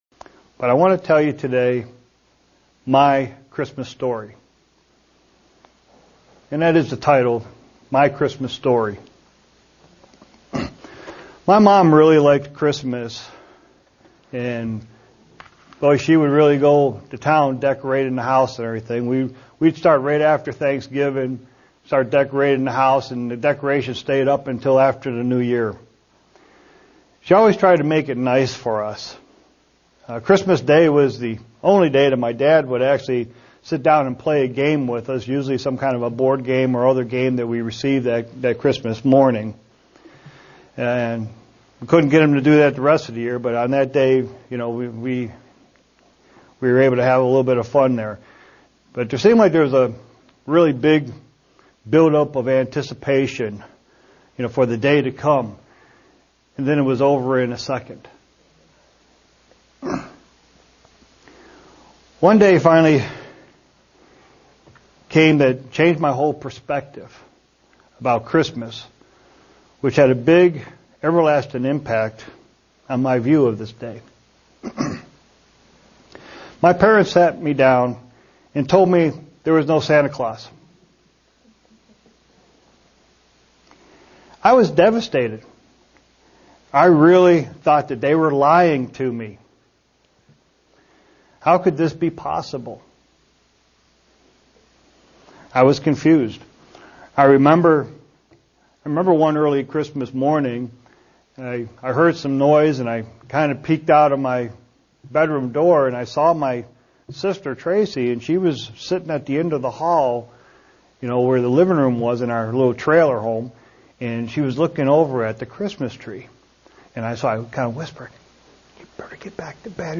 Given in Buffalo, NY
SEE VIDEO BELOW UCG Sermon Studying the bible?